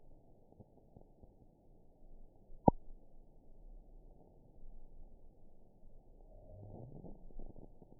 event 912257 date 03/22/22 time 06:07:19 GMT (3 years, 1 month ago) score 5.50 location TSS-AB10 detected by nrw target species NRW annotations +NRW Spectrogram: Frequency (kHz) vs. Time (s) audio not available .wav